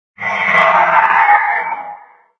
4aef571f59 Divergent / mods / Soundscape Overhaul / gamedata / sounds / monsters / poltergeist / attack_5.ogg 13 KiB (Stored with Git LFS) Raw History
attack_5.ogg